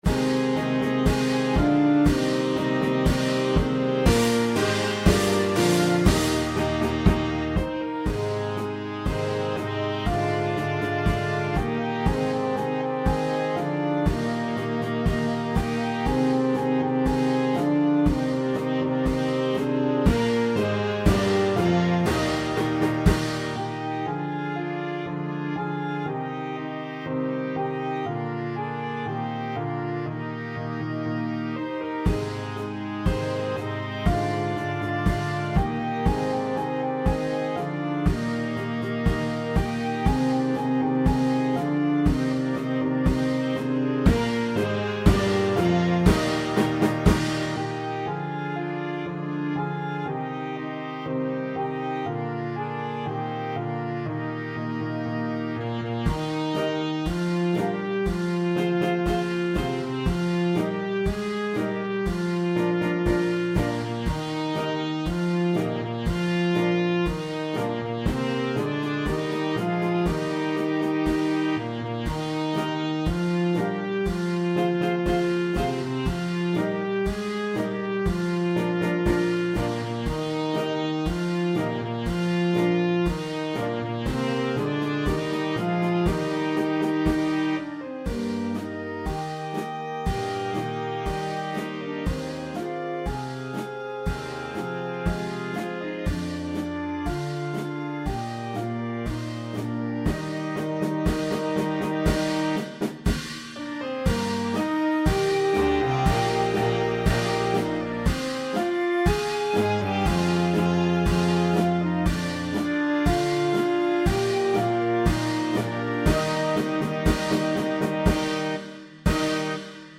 Flute
Oboe
Clarinet 1, 2
Alto Sax
Trumpet 1, 2
Horn in F
Low Brass and Woodwinds
Mallet Percussion
Timpani (2) – Optional
Percussion 2 (Crash Cymbal)
Piano Accompaniment